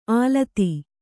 ♪ ālti